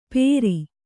♪ pēri